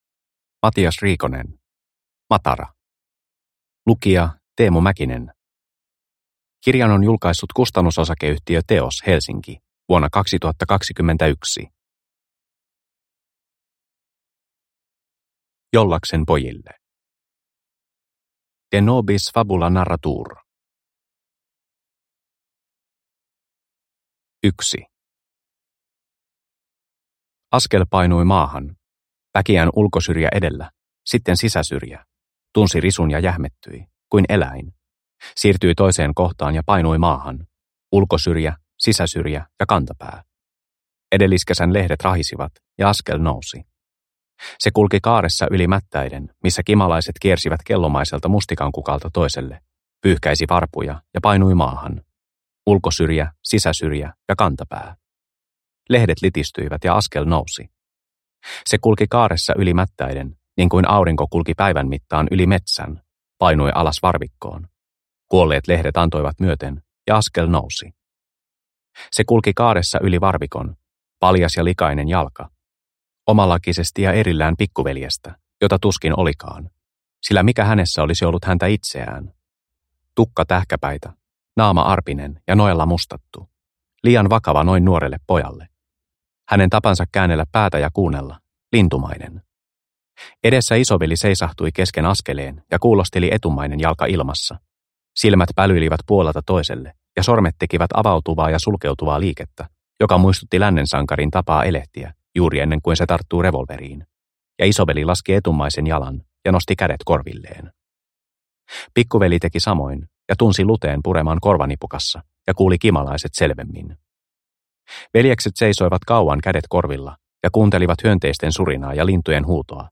Matara – Ljudbok – Laddas ner